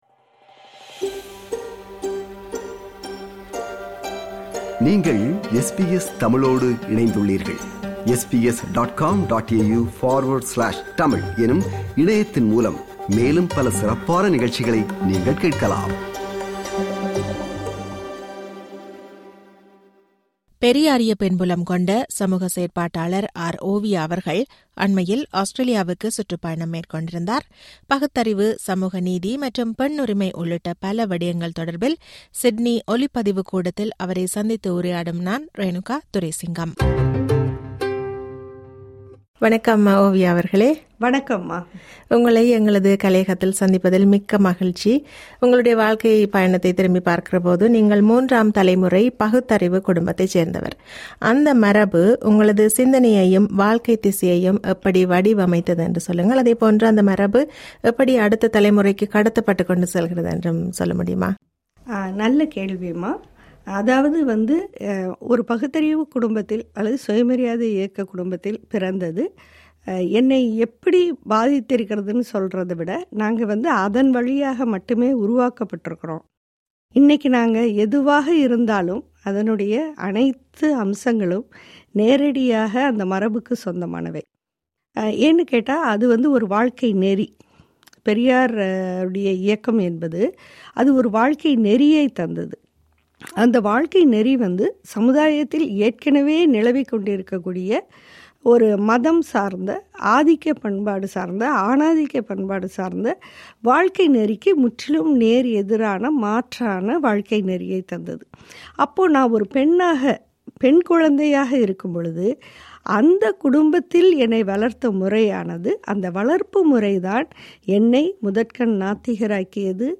பகுத்தறிவு, சமூக நீதி மற்றும் பெண்ணுரிமை உள்ளிட்ட பல விடயங்கள் தொடர்பில் சிட்னி ஒலிப்பதிவுக் கூடத்தில் அவரை சந்தித்து உரையாடுகிறார்